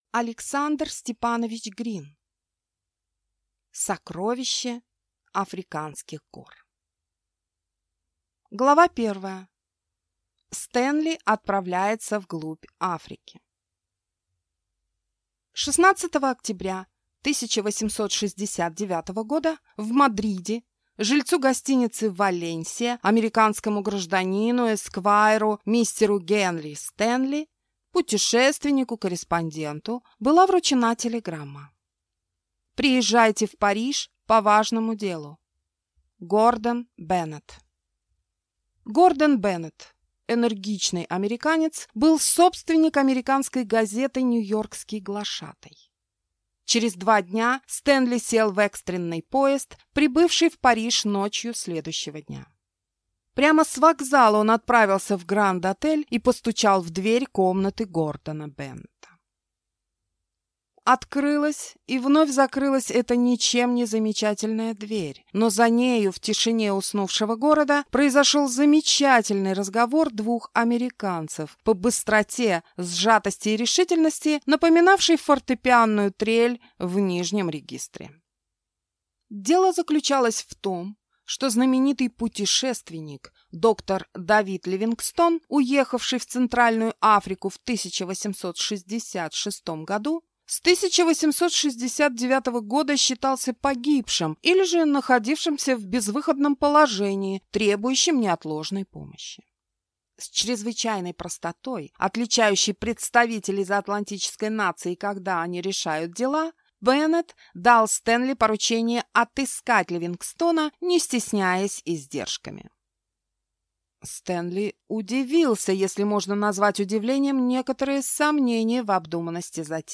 Аудиокнига Сокровище африканских гор | Библиотека аудиокниг